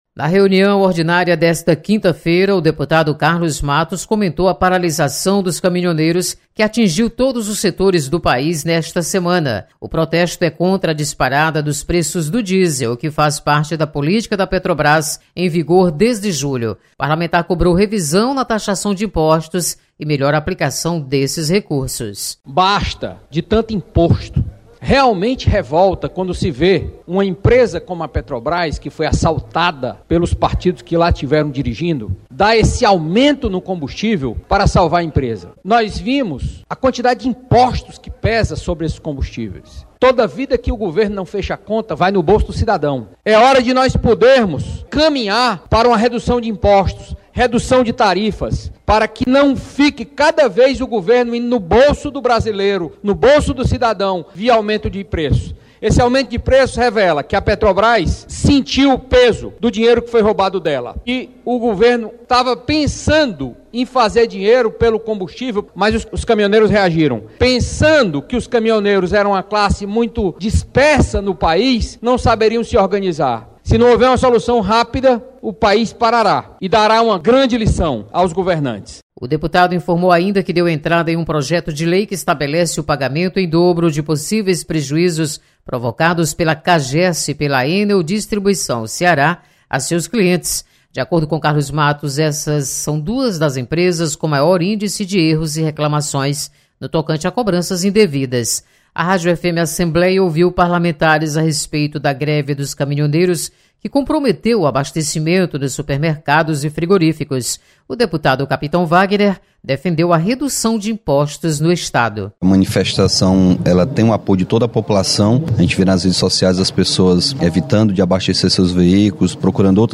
Deputados comentam, em Plenário, sobre paralisação dos caminhoneiros.